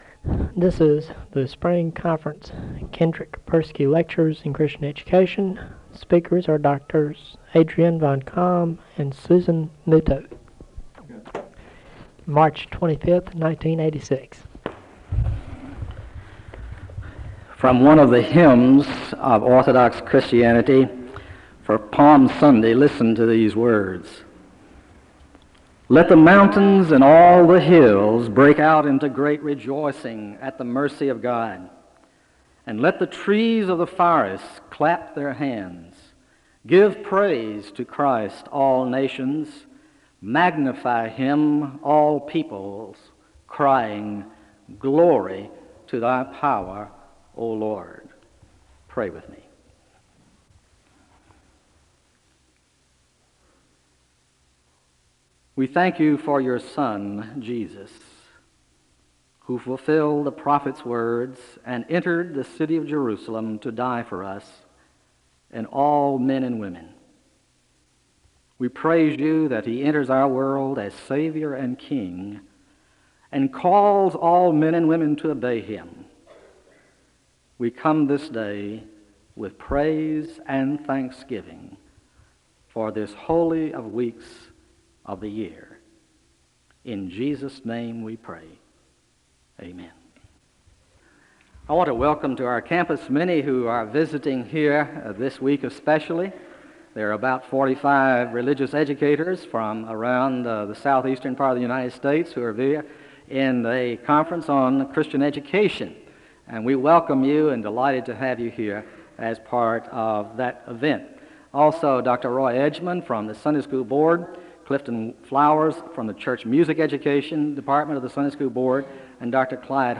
The service begins with the reading of a hymn (0:00-0:52). There is a moment of prayer (0:53-1:30). The guests are welcomed, and announcements are given (1:31-3:10).
They have a moment of stillness (29:03-29:20).
The service closes in a moment of prayer (52:58-53:57).